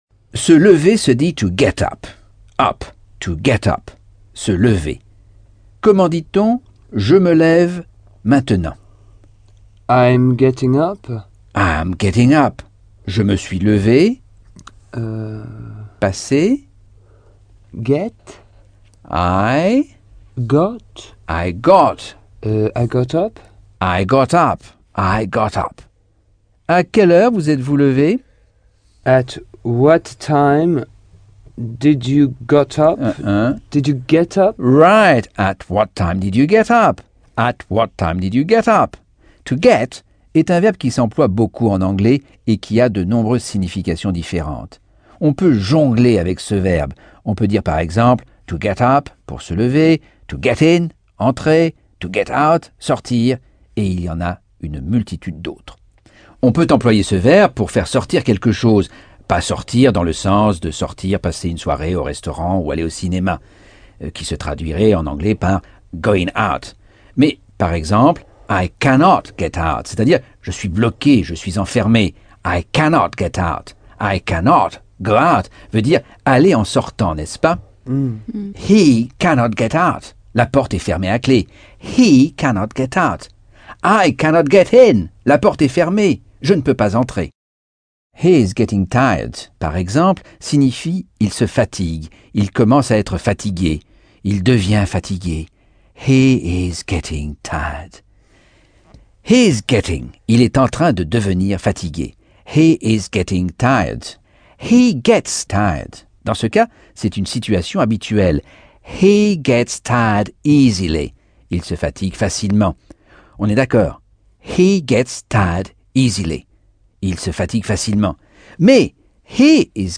Leçon 11 - Cours audio Anglais par Michel Thomas - Chapitre 9